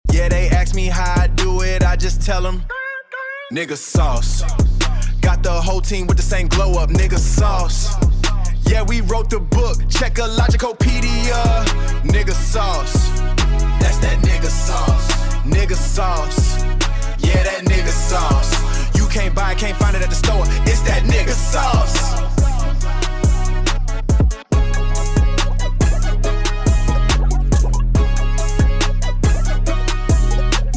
Category:Rap